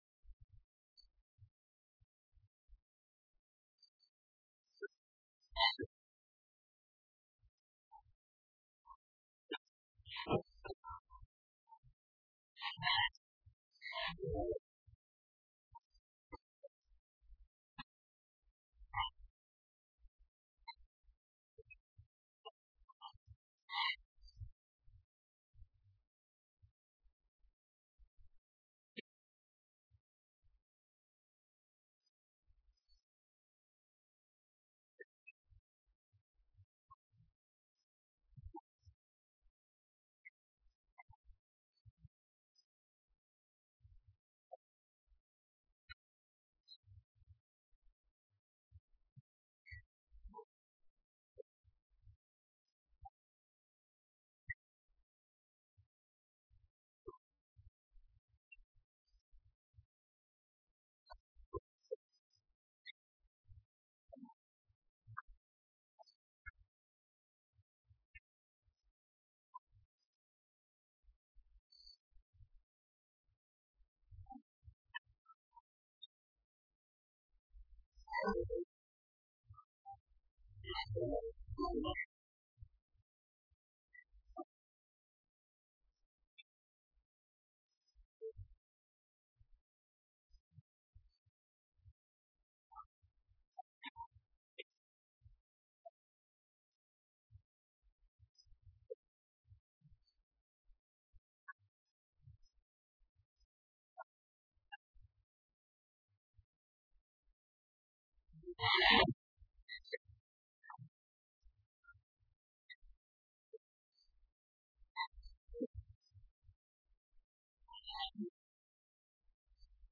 O Vice-Presidente do Governo reiterou hoje, na Assembleia Legislativa, na Horta, que o aumento dos impostos nos Açores foi “uma decisão exclusiva” da Assembleia da República, aprovada pela maioria PSD-CDS/PP.
Sérgio Ávila, que intervinha na sequência de uma Declaração Política do BE sobre próxima entrada em vigor da nova Lei de Finanças Regionais, sublinhou que quem votou a nova lei, “que implica um aumento de impostos na Região, foi exclusivamente o PSD e o CDS/PP e quem votou contra foi o PS, o BE e o PCP”.